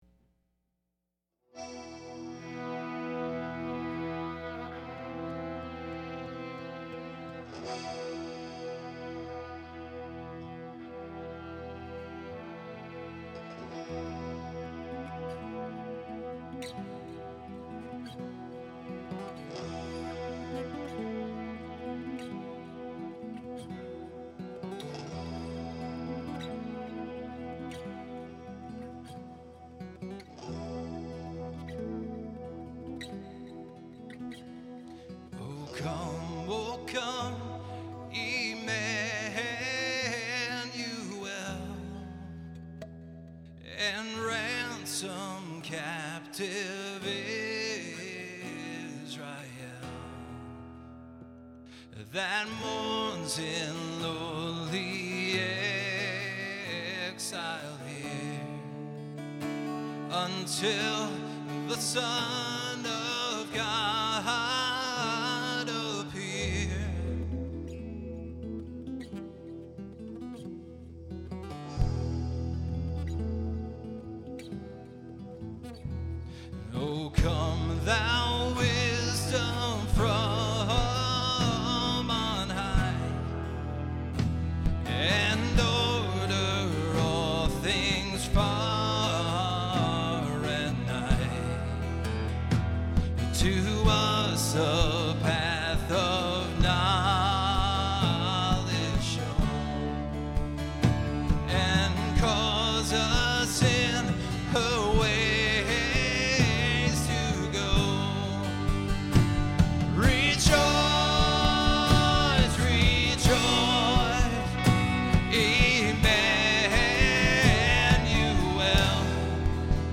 Performed live at Terra Nova - Troy on 11/29/09.